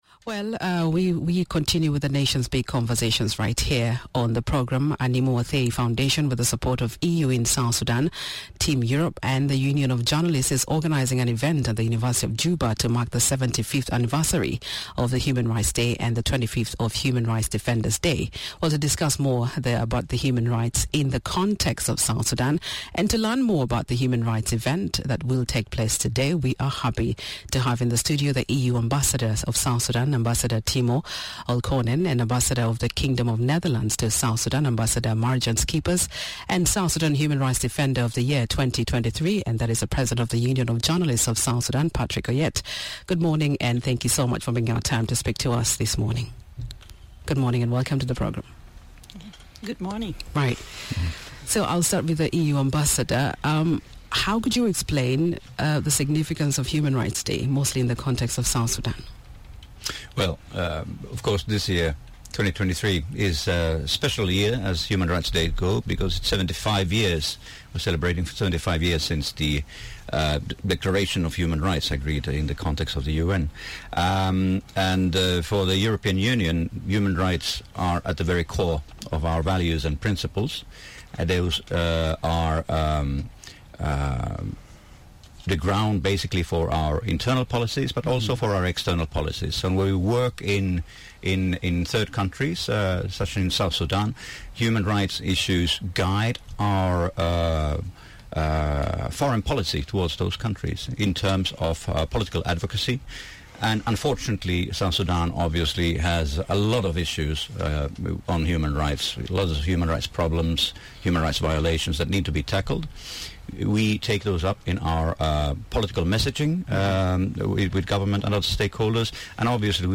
is in conversation with The European Union Ambassador to South Sudan, Timo Olkkonen and Ambassador of the Kingdom of Netherlands to South Sudan, Marjan Schippers.